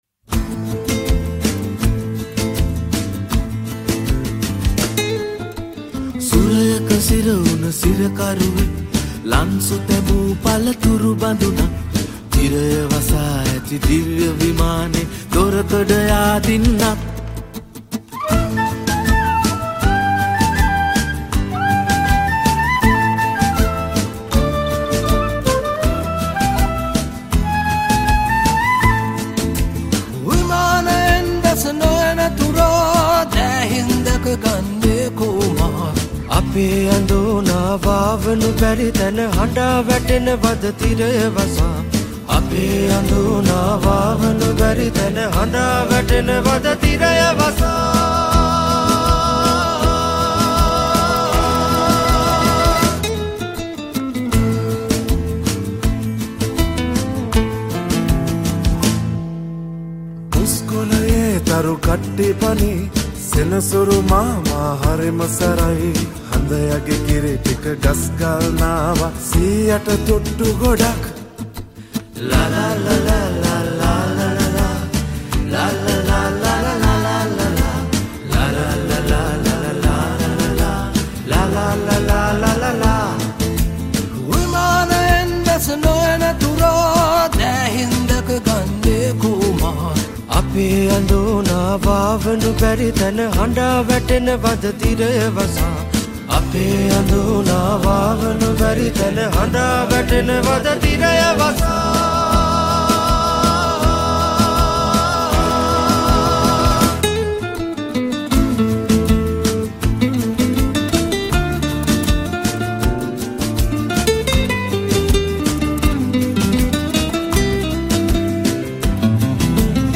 Flamenco Guitars
Flute & Irish Whistle
Keyboard
Bass Guitar
Drums